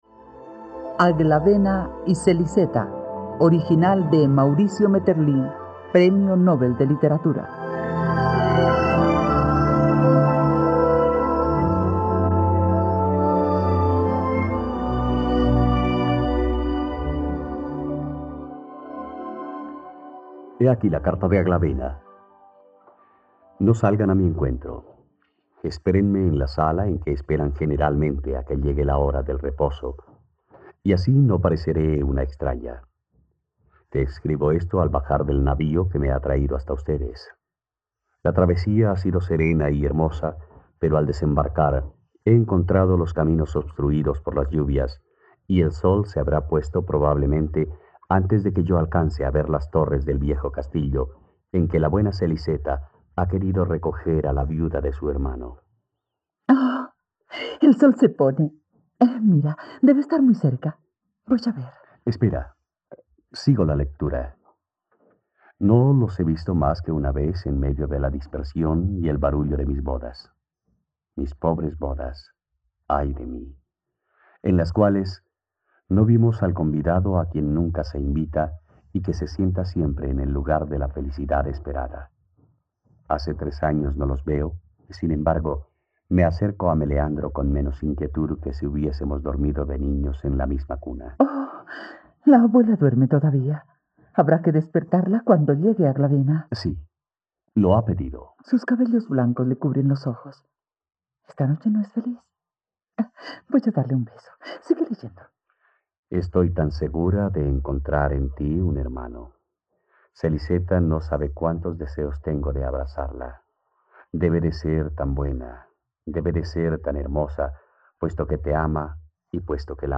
..Radioteatro. Escucha la adaptación de la obra "Aglavena y Seliseta" del ensayista belga Maurice Maeterlinc en la plataforma de streaming RTVCPlay.